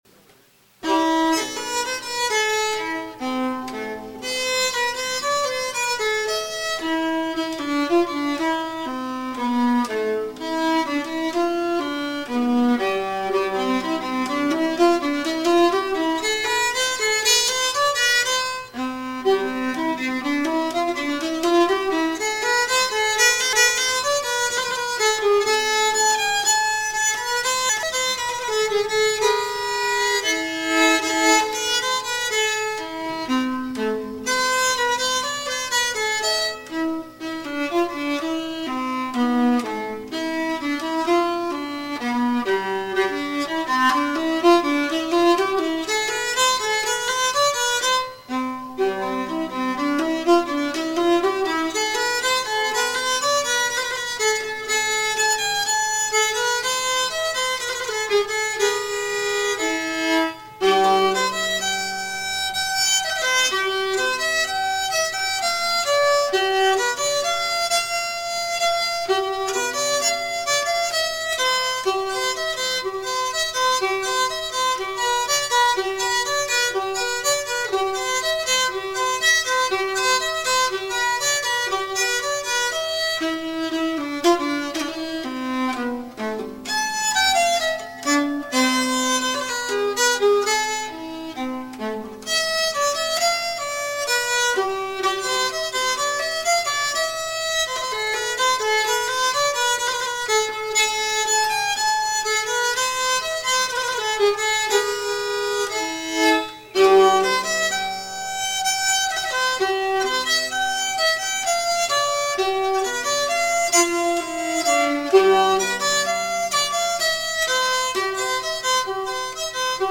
Polska från Järvsö